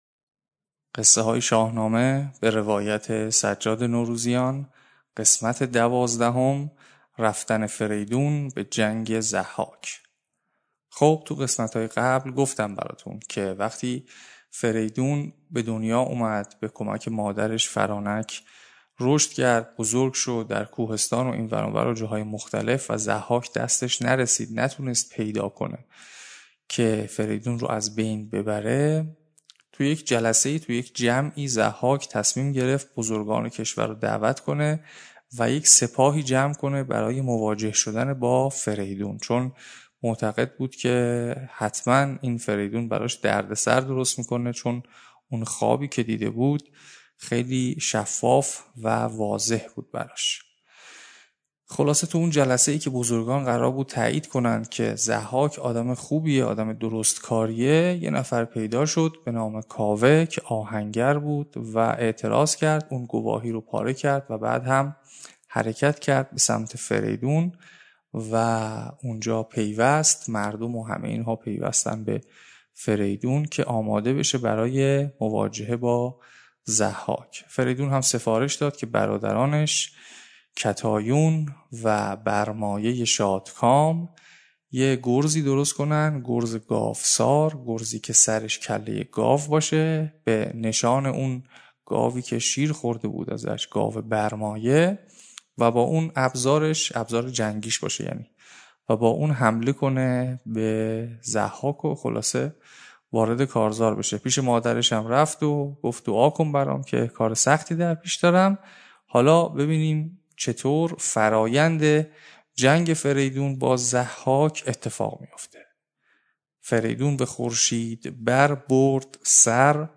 روایتی امروزی از قصه‌های شاهنامه - همراه با خوانش شعر